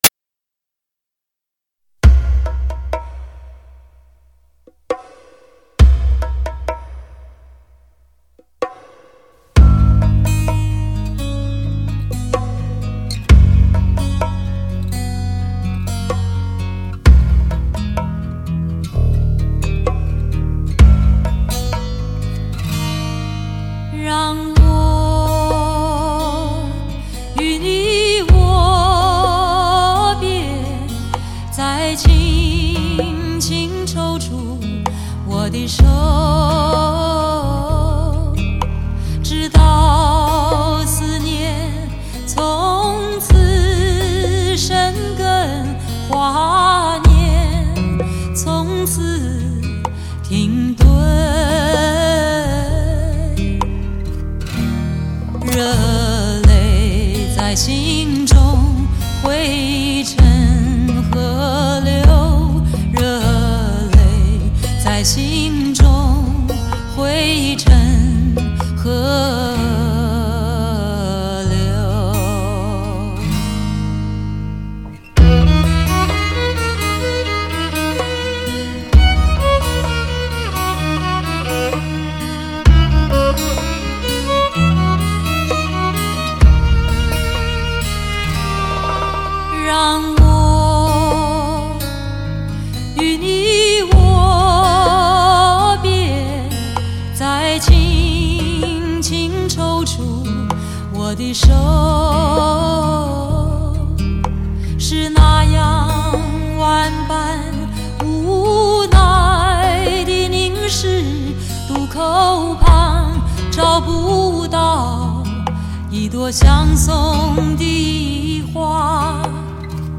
HI-FI顶级人声测试大碟
XRCD2
旷世巨星 跨越三个十年 记录鼎盛时期的琴歌 琴情捕捉磁性动人的嗓音 品味琴式阴柔沉静的美感